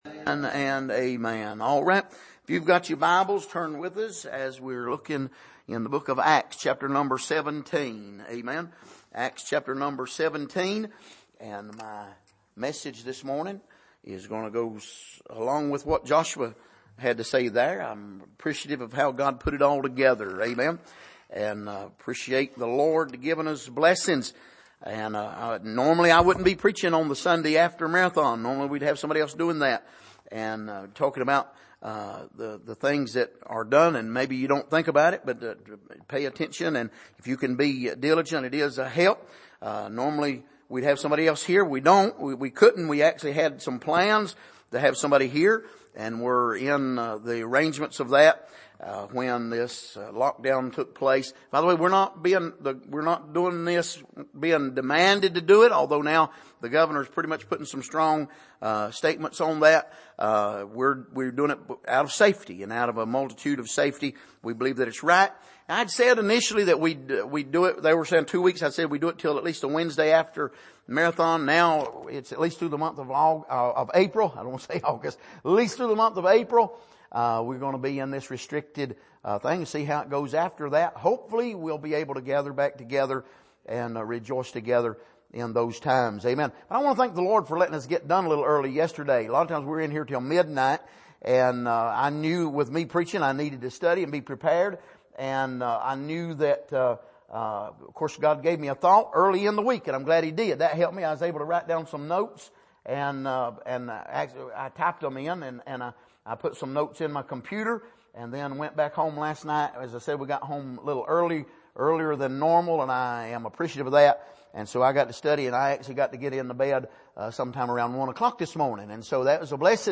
Passage: Acts 17:1-8 Service: Sunday Morning